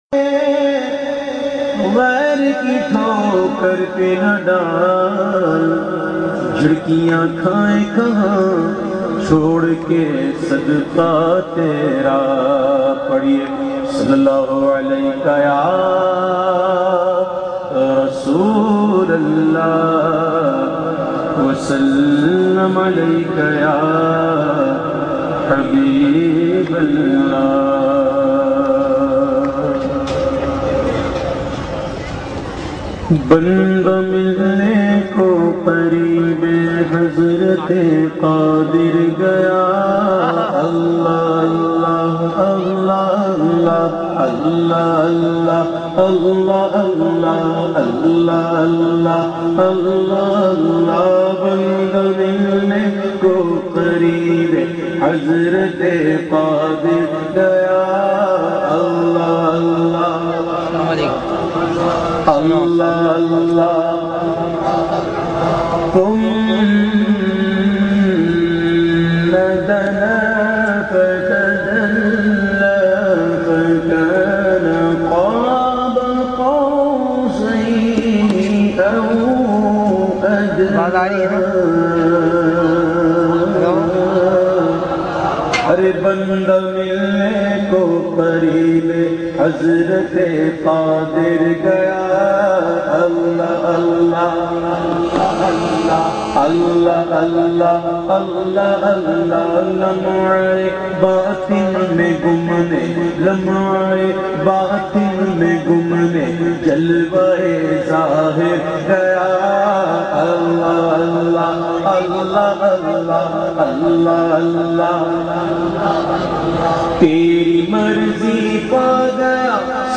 Mehfil-e-Naat - Gyarween Shareef 2009